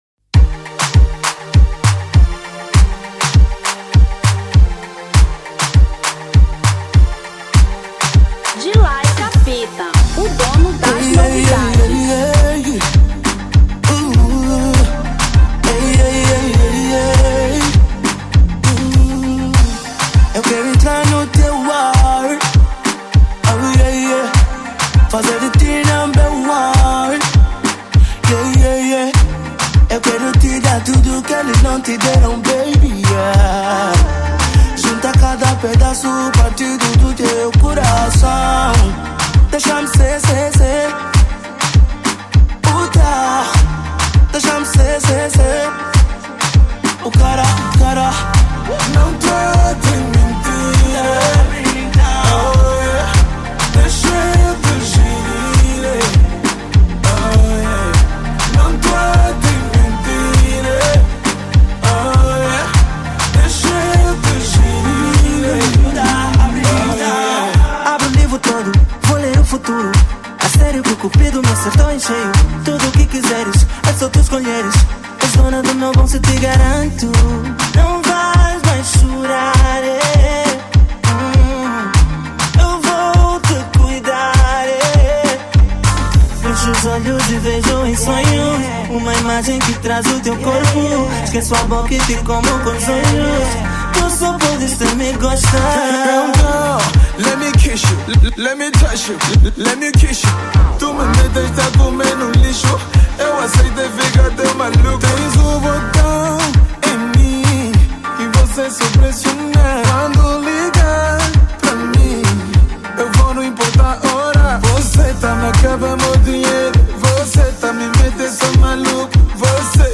Kizomba 2017